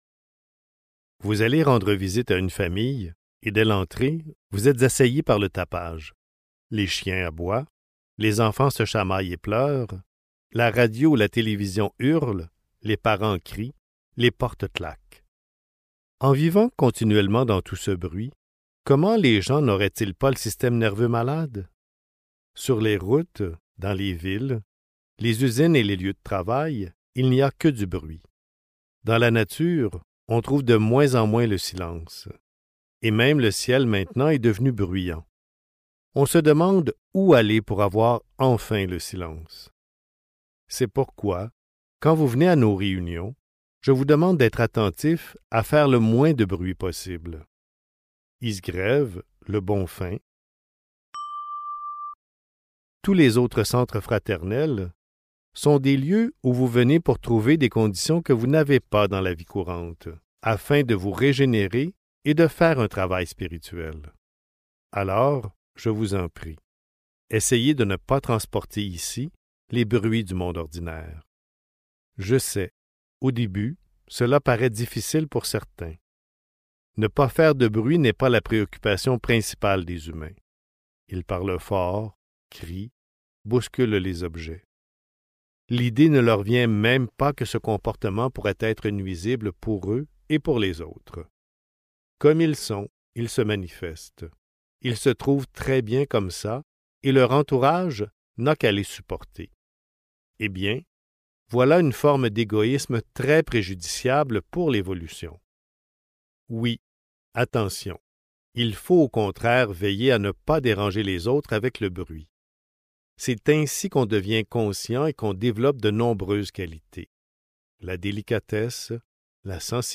La voie du silence (Livre audio | Téléchargement) | Omraam Mikhaël Aïvanhov